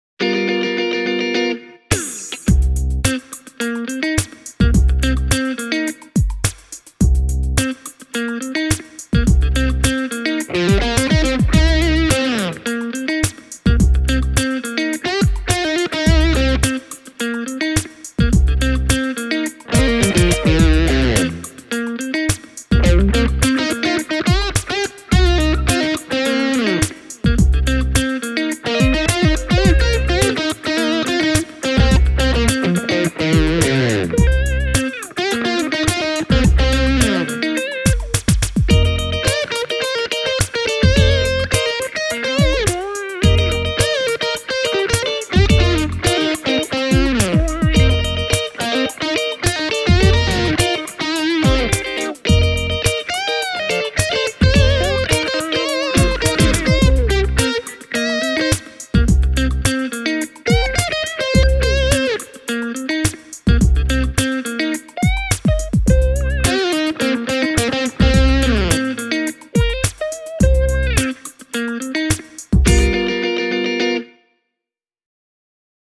ESP USA Eclipse on varustettu kahdella Seymour Duncan Alnico II Pro -humbuckereilla (APH-1), jotka ovat vintage-tyyliset, lämpimät mikrofonit (ja esimerkiksi Slashin lempimikit).
Jenkki-Eclipsen soitettavuus on mielestäni ensiluokkaista – kevyt, muttei löysä – ja kitara soi puhtaasti ja kauniisti koko otelaudassa.
Demobiisissä vasemmalta tuleva komppikitara käyttää molempia puolitettua mikrofonia yhdessä, kun taas oikealta tulevissa wah-osuuksissa soi puolitettu kaulamikki yksin. Särökitara käyttää täyttä tallamikrofonia: